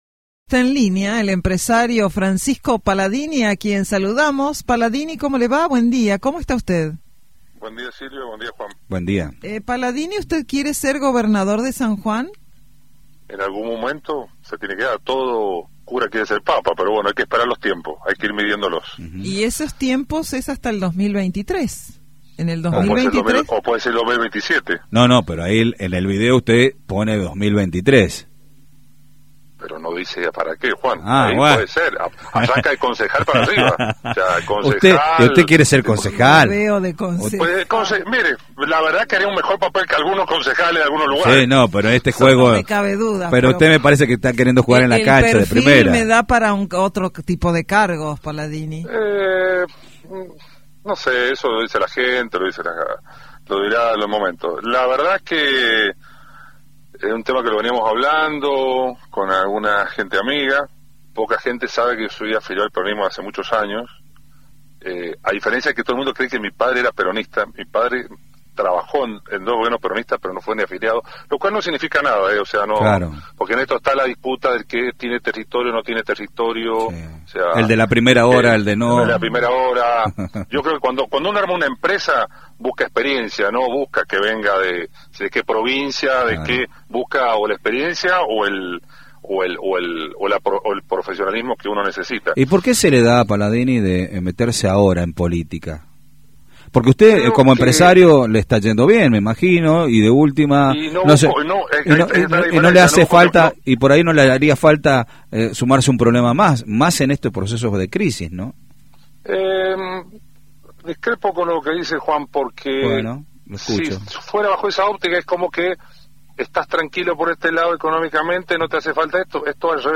dialogó con los periodistas de Radio Sarmiento acerca de la posibilidad de ingresar en el mundo político como una propuesta más para 2023.